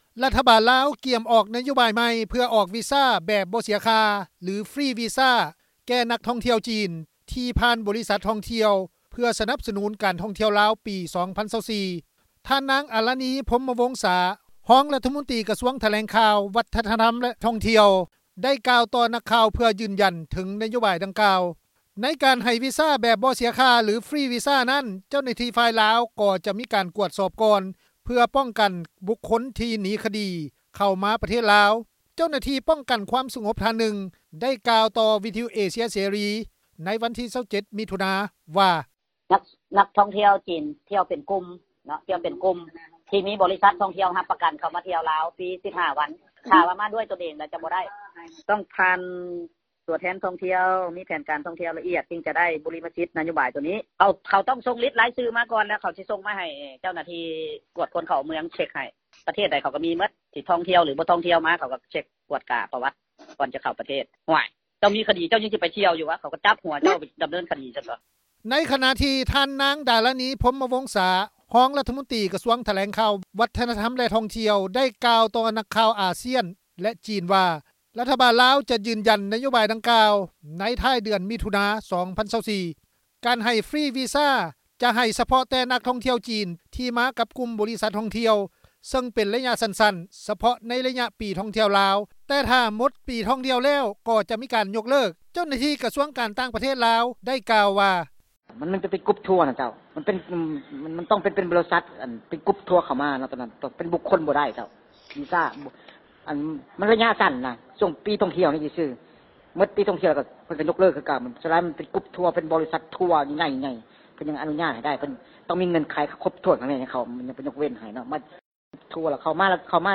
ເຈົ້າໜ້າທີ່ ປ້ອງກັນຄວາມສະຫງົບ ທ່ານໜຶ່ງ ໄດ້ກ່າວຕໍ່ວິທຍຸເອເຊັຽເສຣີ ໃນວັນທີ 27 ມິຖຸນາ ວ່າ:
ເຈົ້າໜ້າທີ່ ກະຊວງການຕ່າງປະເທດລາວ ໄດ້ກ່າວວ່າ:
ຊາວແຂວງຫລວງພຣະບາງ ໄດ້ກ່າວວ່າ:
ເຈົ້າໜ້າທີ່ປ້ອງກັນຄວາມສະຫງົບ ໄດ້ກ່າວວ່າ: